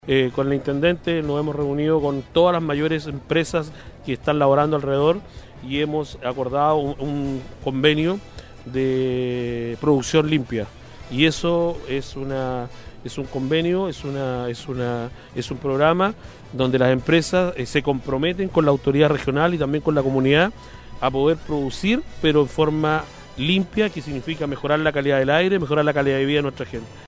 Morales Carrasco, una finalizada la ceremonia solemne, comentó con Nostálgica que se sentía grato de contar con la presencia del Intendente en la actividad, de parlamentarios de la zona, dirigentes y vecinos y sobre todo de las buenas noticias que se compartieron con la comunidad:
El alcalde destacó que dentro de los próximos días se comenzarán a inaugurar algunas obras, lo que refleja que la comuna comienza a recuperar su infraestructura y la alegría, donde los temas de educación y  salud son esenciales, realizando además un anuncio de un importante convenio con las empresas de la zona: